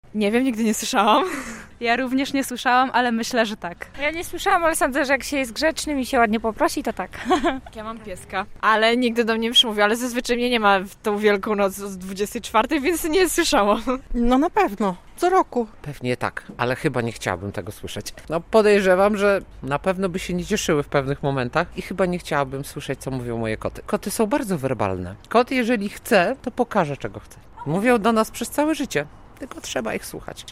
Czy zwierzęta mówią w wigilię ludzkim głosem? - sonda